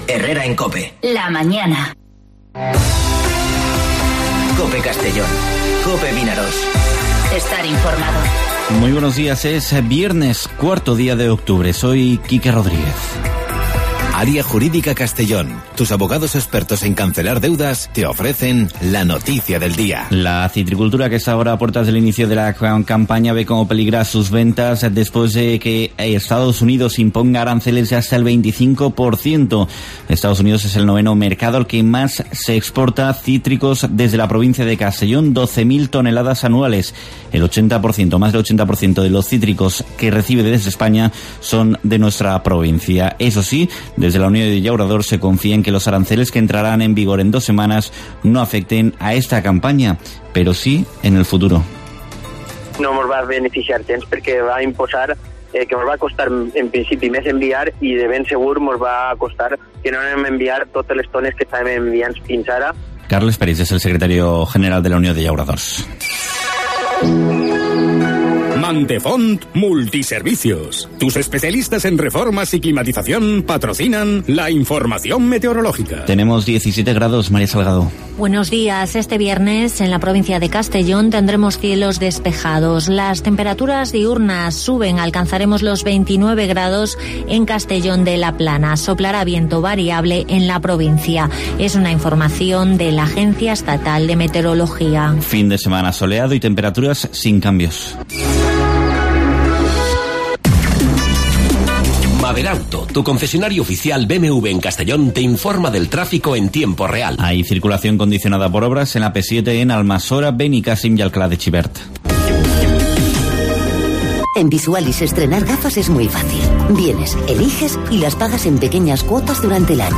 Informativo Herrera en COPE Castellón (04/10/2019)